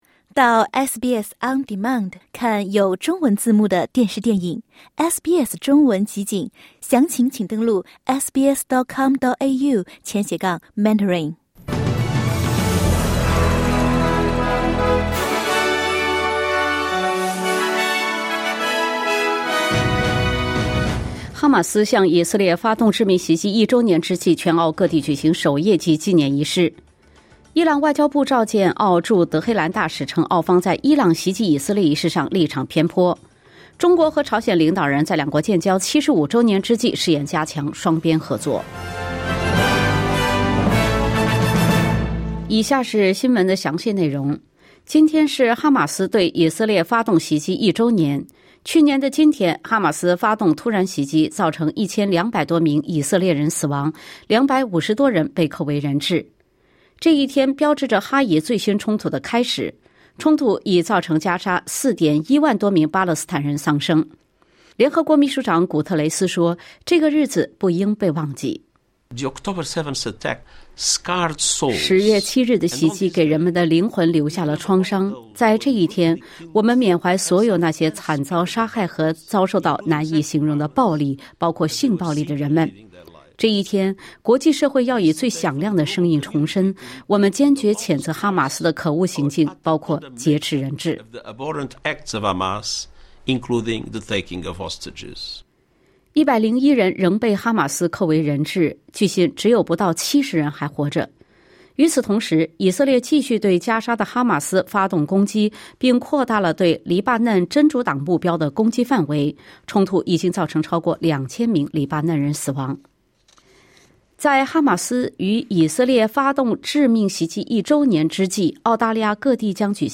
SBS早新闻（2024年10月7日）